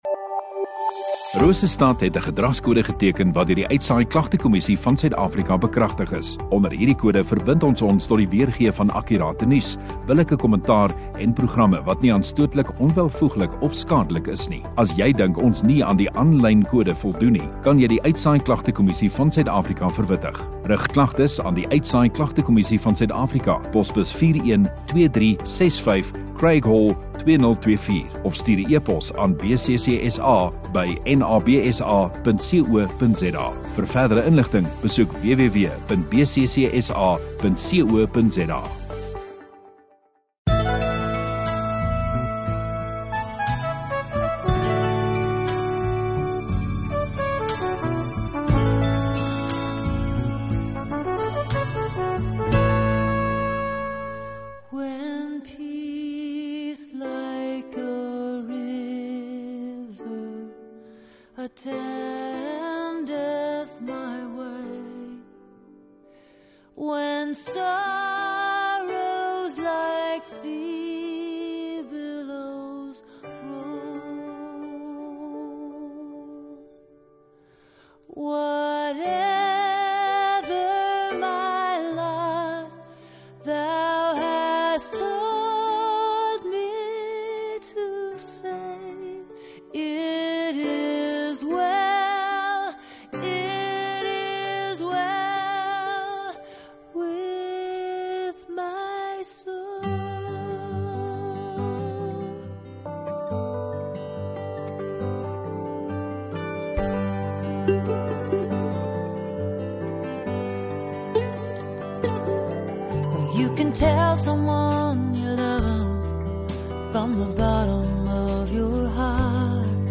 17 May Saterdag Oggenddiens